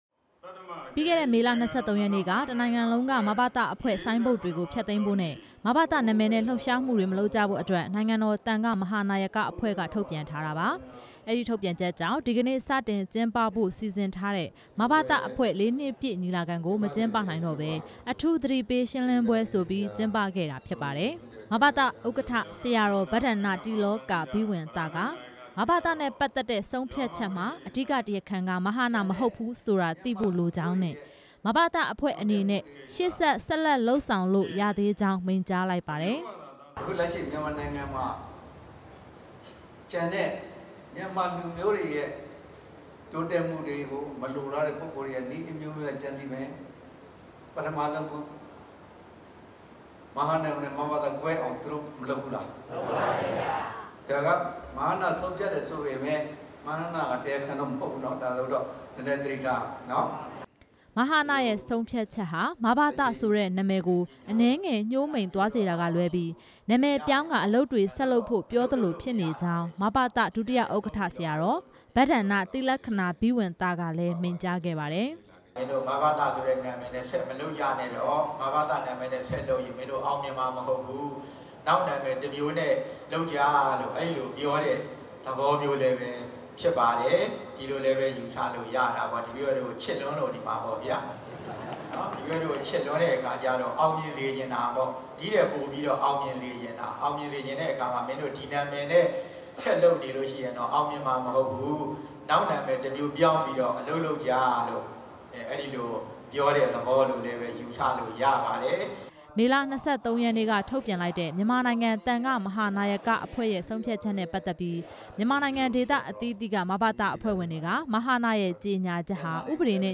ဒီကနေ့ ရန်ကုန်မြို့ အင်းစိန်မြို့နယ်မှာရှိတဲ့ အောင်ဆန်းတောရတပ်ဦးကျောင်းတိုက်မှာ ကျင်းပခဲ့တဲ့ အမျိုးဘာသာ သာသနာစောင့်ရှောက်ရေး အဖွဲ့(ဗဟို) မဘသ အဖွဲ့ဝင်တွေကို အထူးအသိပေး ရှင်းလင်းပွဲမှာ မဘသ ဥက္ကဌ ဆရာတော်က အဲဒီလို မိန့်ကြားလိုက်တာ ဖြစ်ပါတယ်။